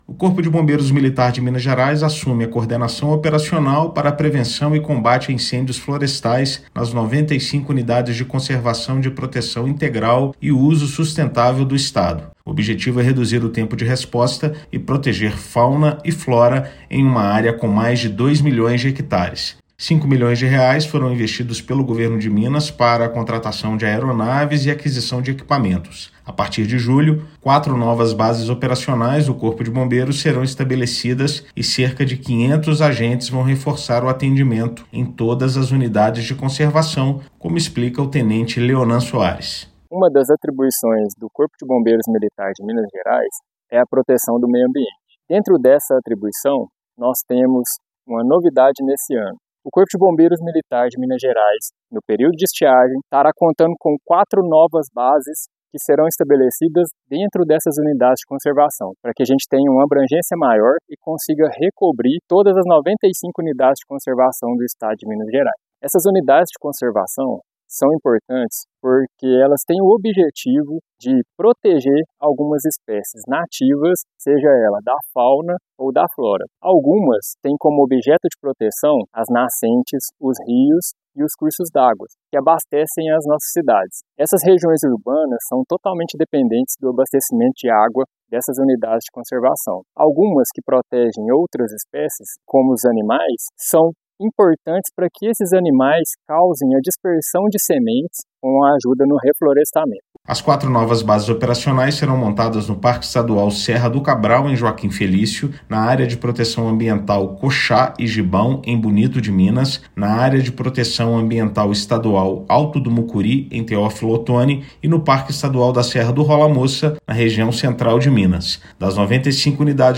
[RÁDIO] Com bases operacionais, Bombeiros querem agilizar atuação em áreas de incêndios em Minas Gerais
Corporação vai contar com o reforço de 500 agentes para otimizar atendimento e reduzir danos ao meio ambiente. Ouça matéria de rádio.